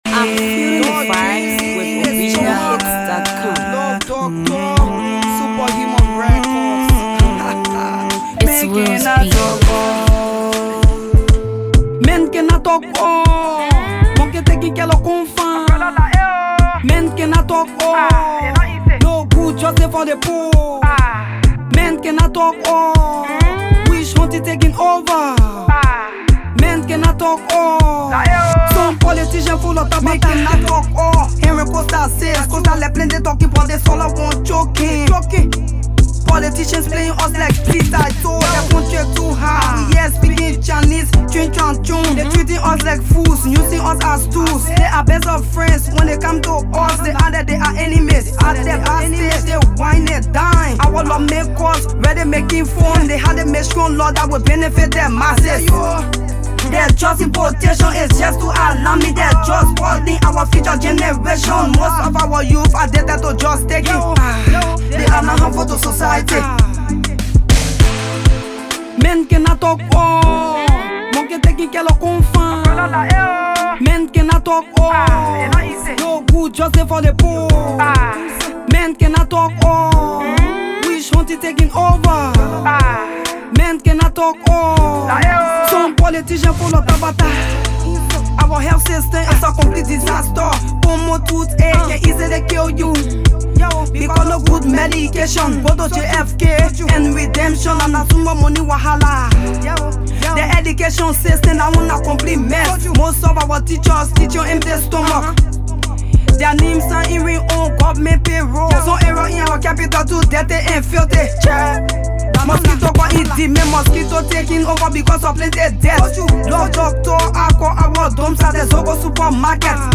versatile female Hipco artist